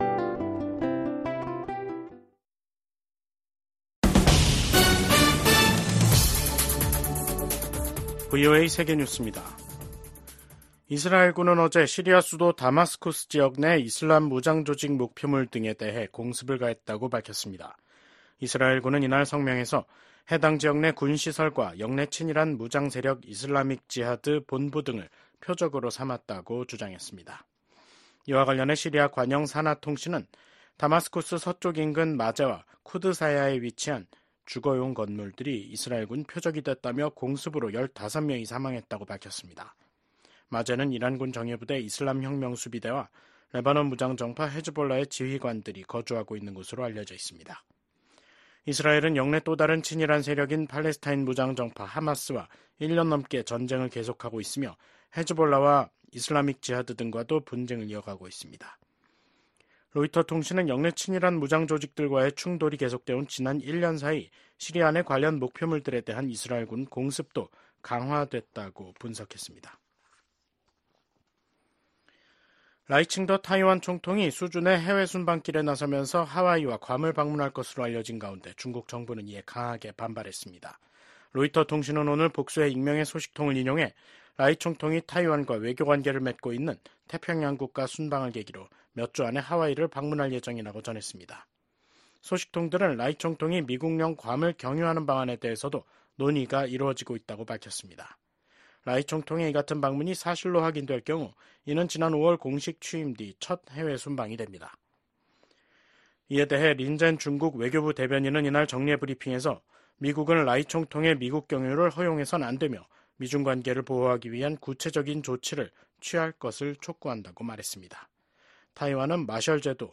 VOA 한국어 간판 뉴스 프로그램 '뉴스 투데이', 2024년 11월 15일 3부 방송입니다. 미국 국무장관이 한국, 일본, 캐나다 외교장관 등과 잇따라 만나 북한군 파병 문제를 논의했습니다. 김정은 북한 국무위원장은 석달 만에 또 자폭 공격형 무인기 성능시험 현지지도에 나서 이 무인기의 본격적인 대량생산을 지시했습니다.